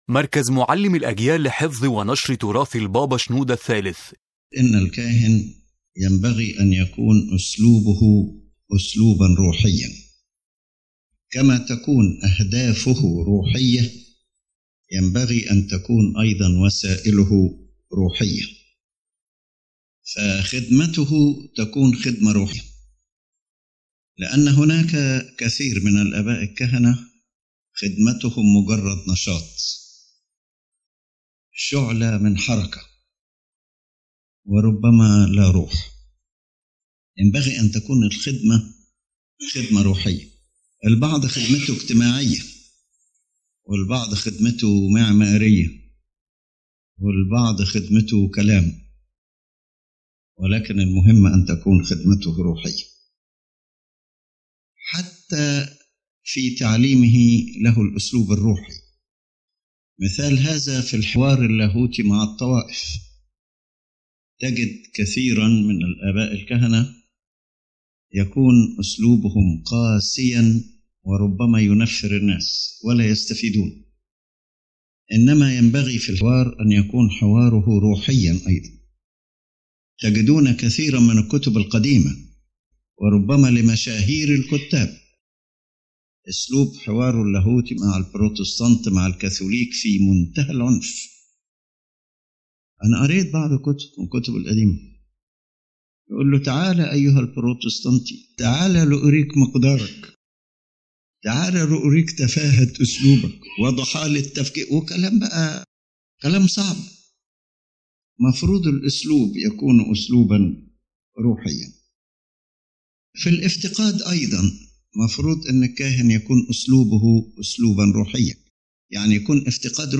General Message of the Lecture